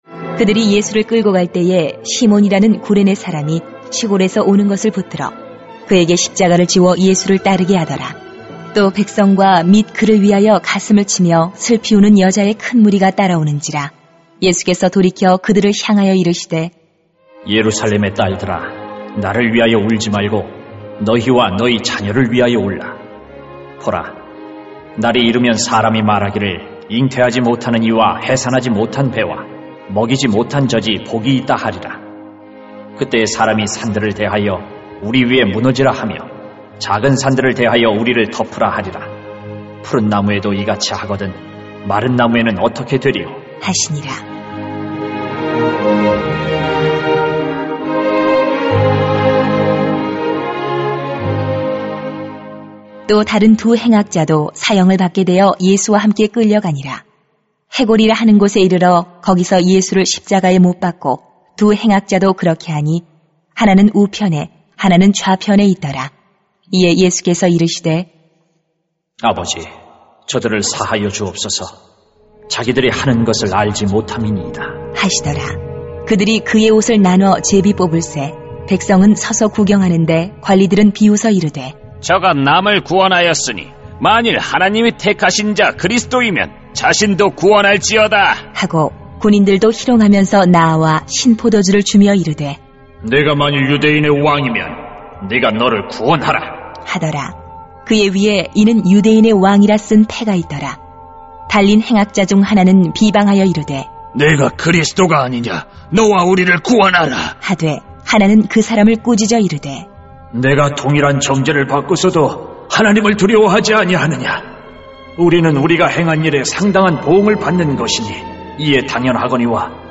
[눅 23:26-43] 예수님은 자기 백성을 구원해 주십니다 > 새벽기도회 | 전주제자교회